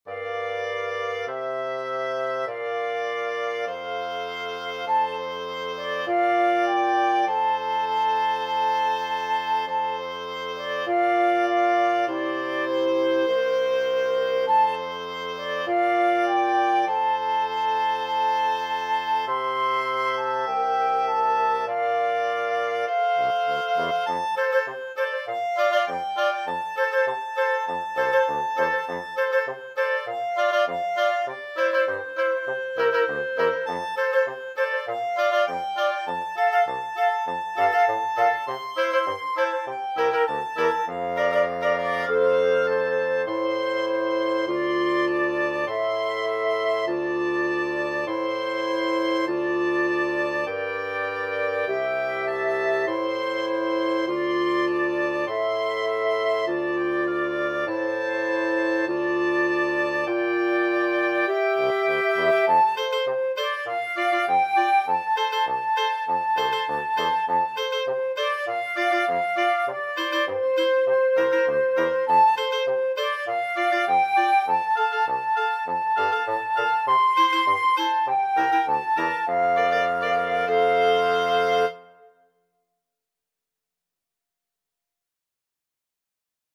FluteOboeClarinetBassoon
4/4 (View more 4/4 Music)
Moderato =c.100